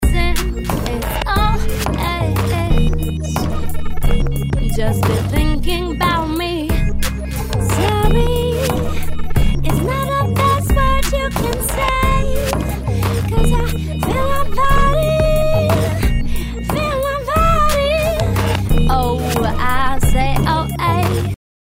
Full Mix mit Vocals, 90 BPM